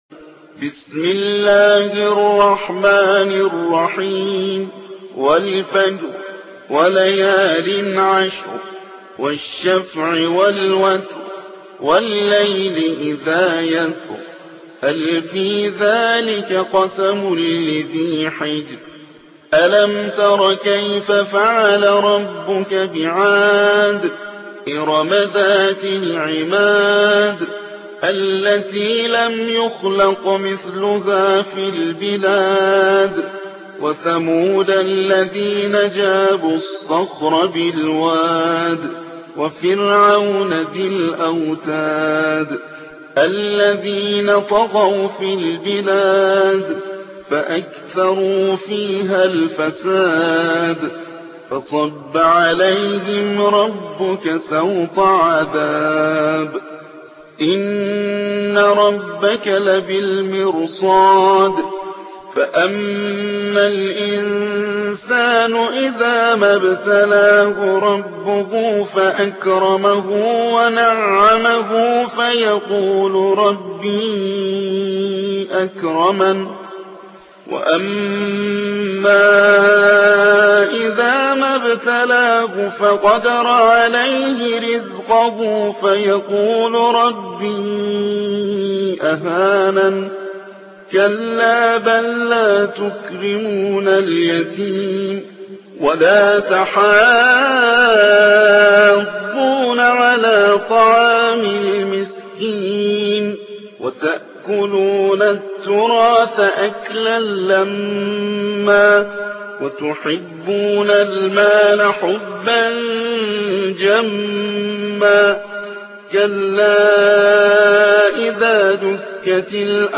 89. سورة الفجر / القارئ